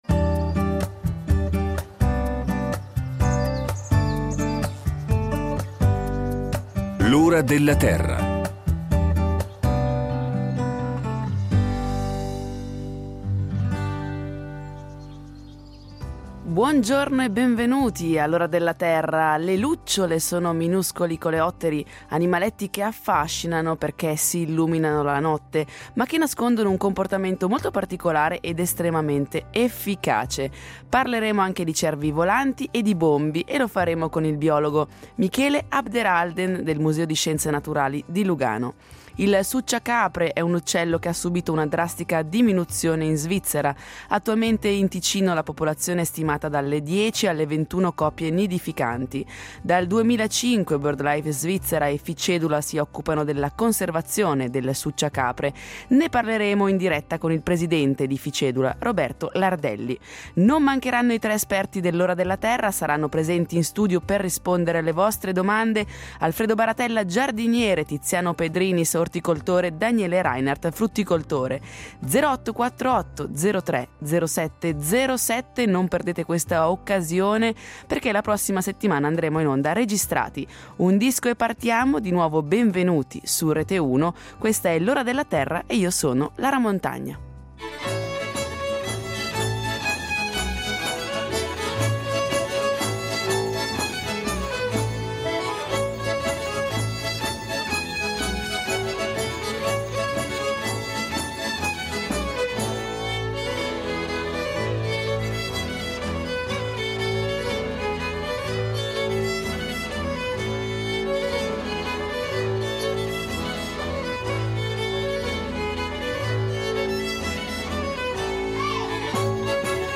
I tre esperti de L’ora della Terra saranno presenti in studio per rispondere alle domande del pubblico da casa: